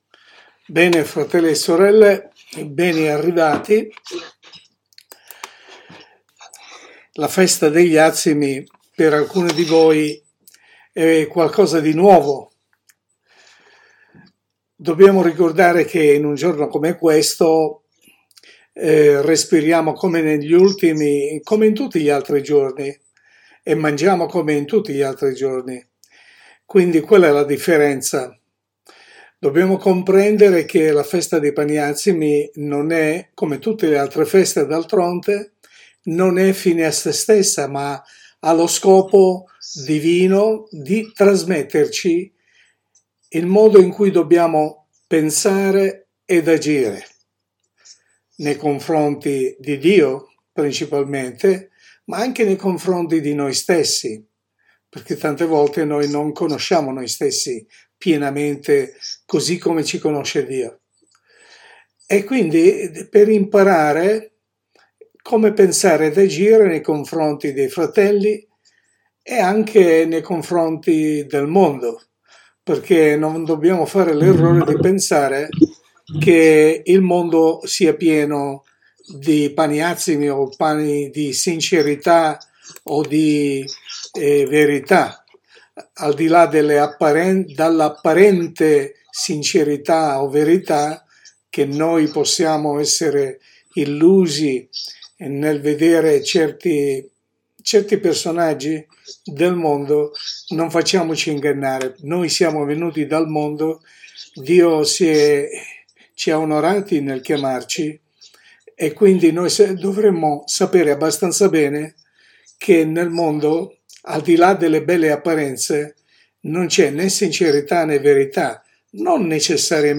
Sermone pastorale